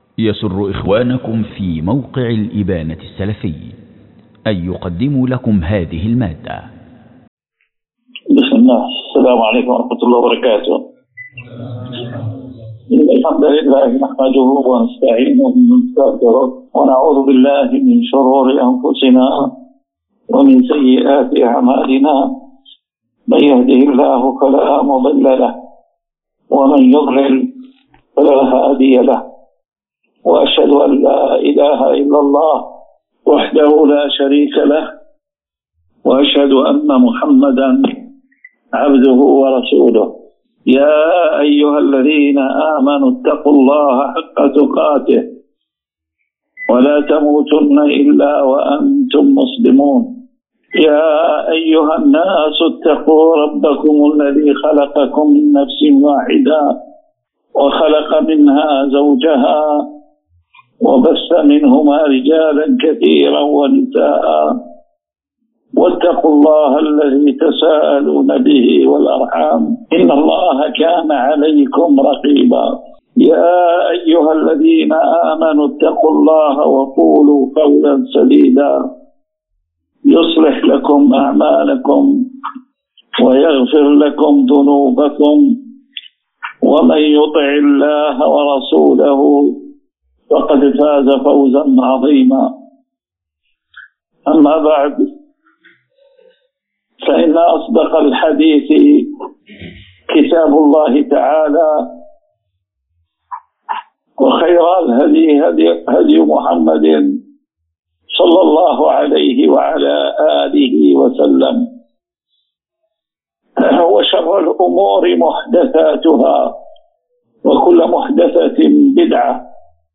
🗓ليلة الأربعاء 1 جمادى الأولى 1447 هــ الموافق ل22 أكتوبر 2025 م 🔹حاسي خليفة ولاية الوادي
دروس ومحاضرات